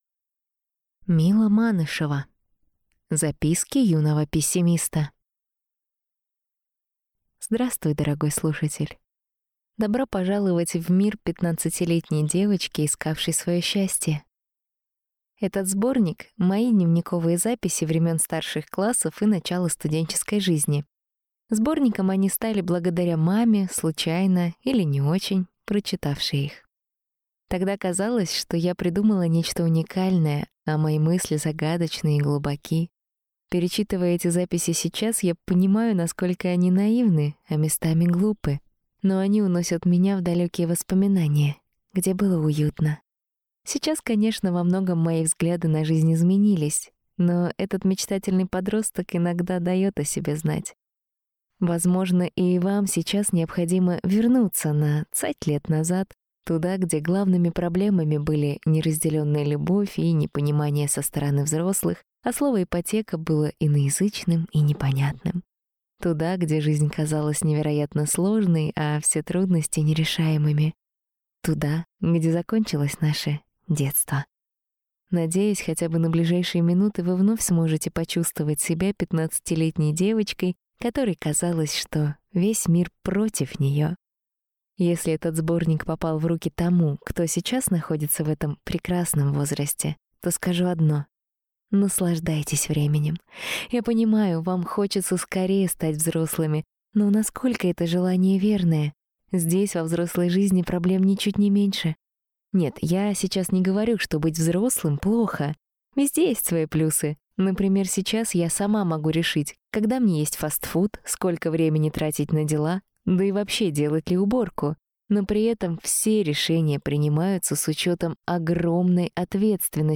Аудиокнига Записки юного пессимиста | Библиотека аудиокниг